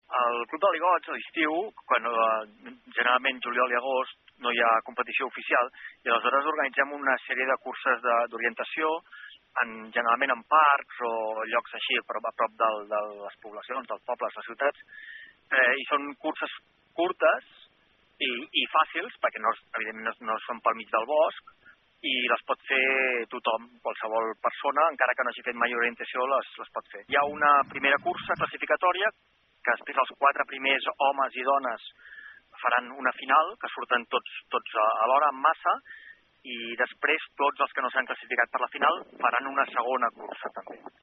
N’ha parlat